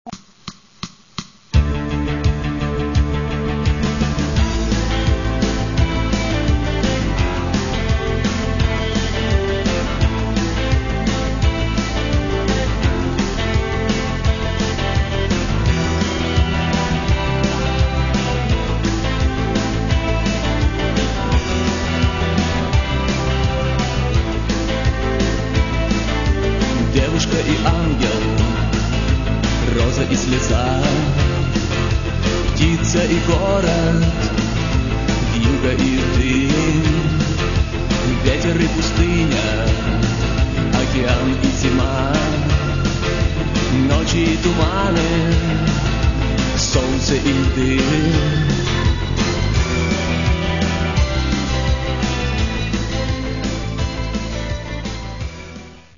Catalogue -> Rock & Alternative -> Compilations